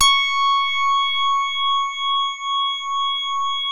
JAZZ HARD#C5.wav